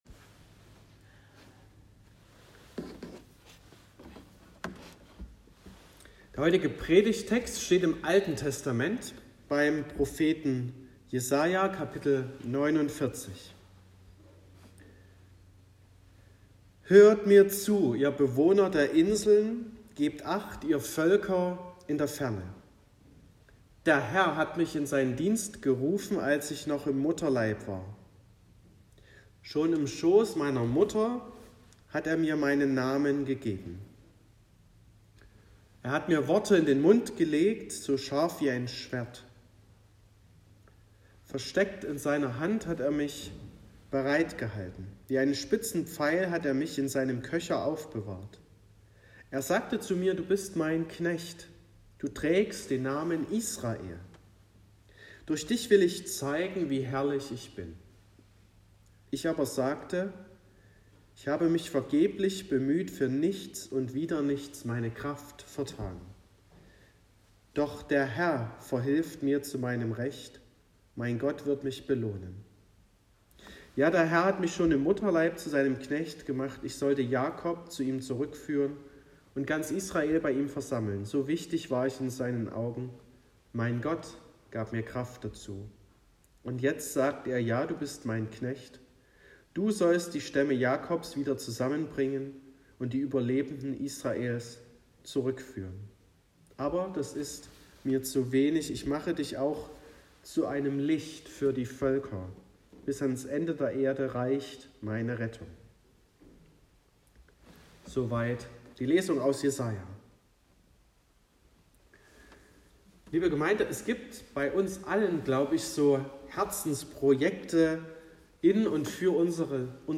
09.10.2022 – Gottesdienst
Predigt (Audio): 2022-10-09_Jesaja.m4a (8,3 MB)